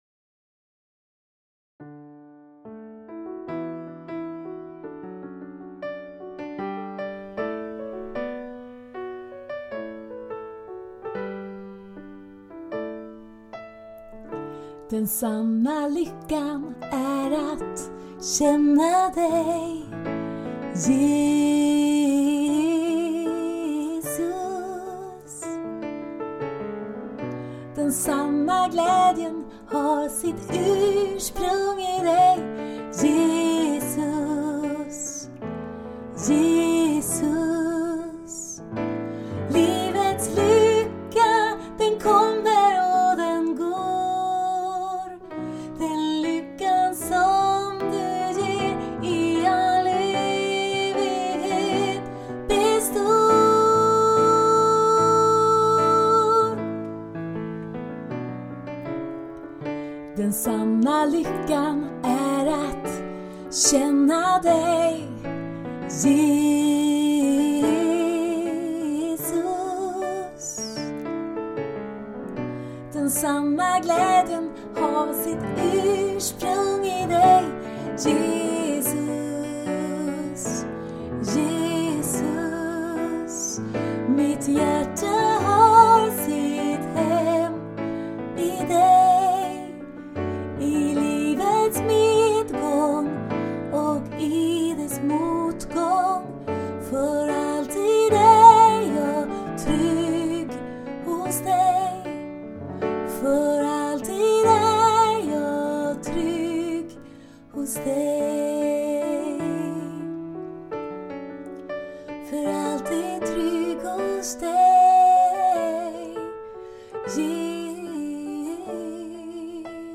Här finns några moderna nya psalmer i enkla inspelningar.
Denna sång är kanske mer en stilla lovsång, en sång av stilla förtröstan, men stillheten i sången gör att jag tänker att den också kan vara en psalm.